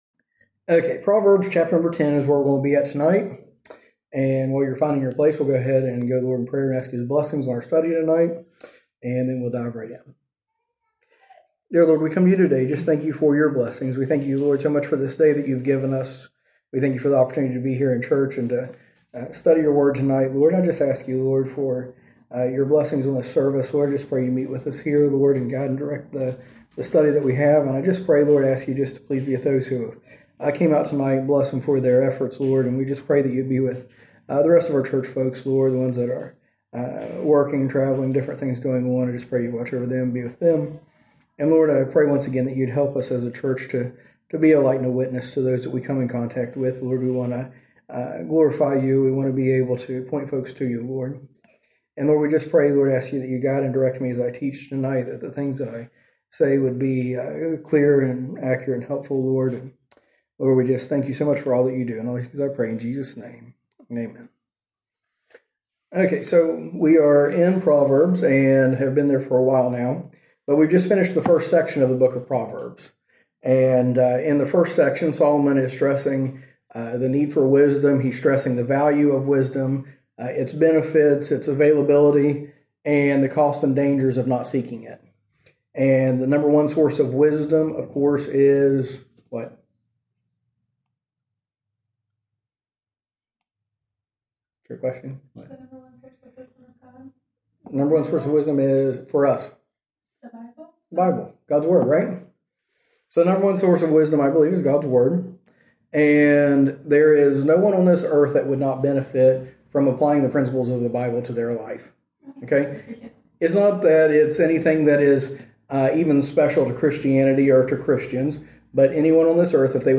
A message from the series "Proverbs."